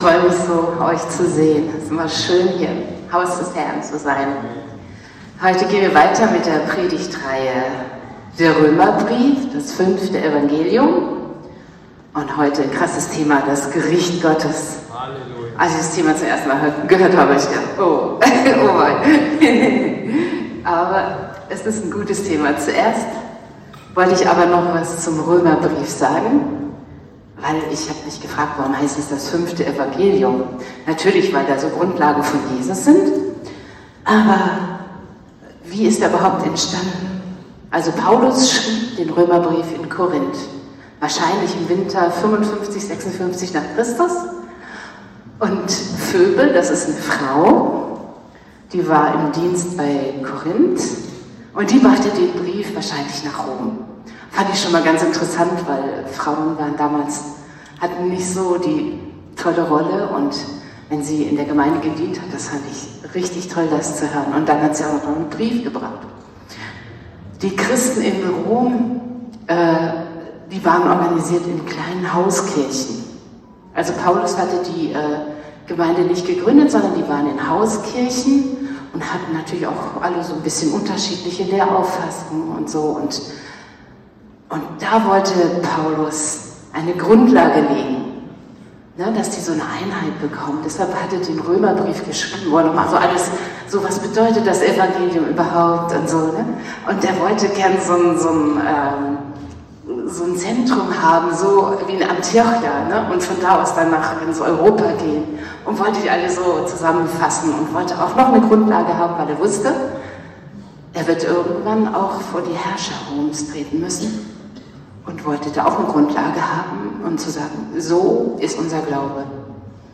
Predigtreihe: Der Römerbrief – Das 5. Evangelium